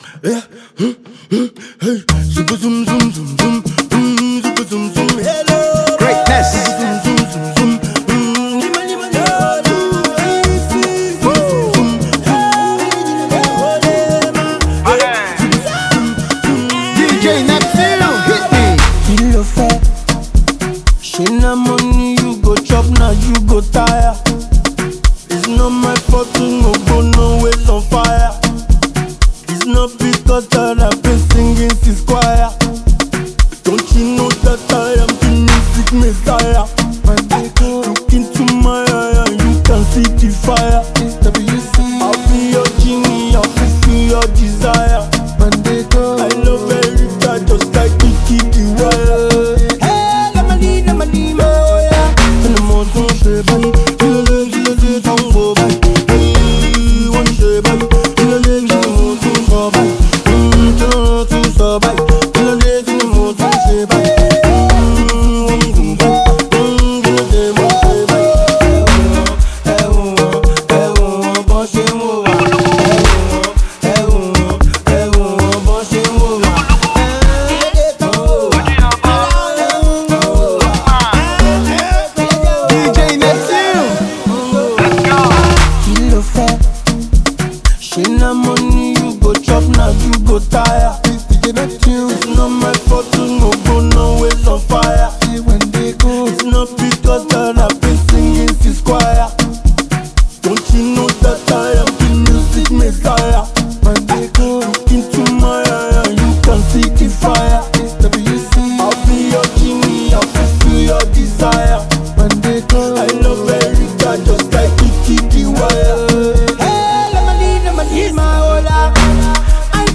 is an Afro amapiano influenced tune